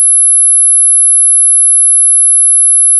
Anhänge audiocheck.net_sin_11000Hz_-3dBFS_3s.wav 258,4 KB · Aufrufe: 139